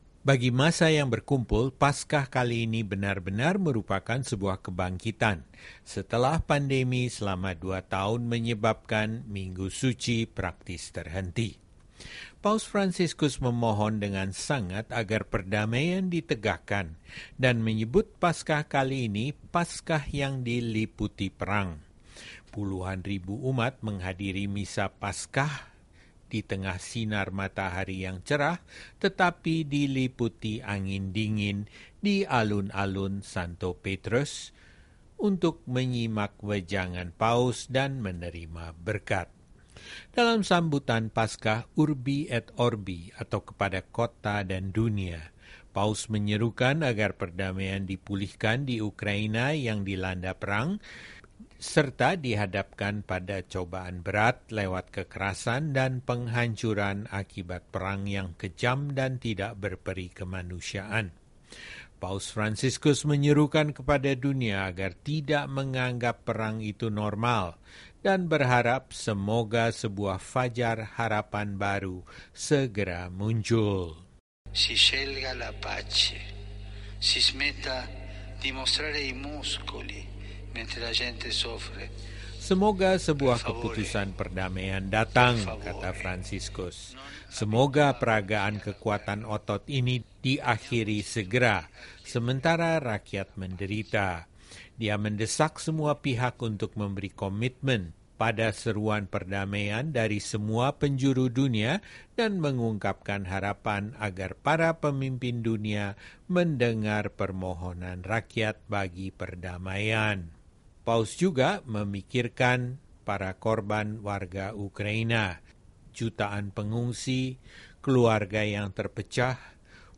Berbicara dari balkon tengah yang menghadap ke Lapangan Santo Petrus, Paus mengatakan “semoga ada perdamaian untuk Ukraina yang dilanda perang, yang telah diuji dengan kekerasan dan penghancuran yang kejam dan tidak masuk akal.”
Puluhan ribu umat menghadiri misa Paskah kali ini di tengah sinar matahari cerah tetapi diliputi angin dingin di Alun-Alun Santo Petrus, untuk menyimak wejangan Paus dan menerima berkat.